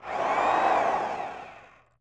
drill.wav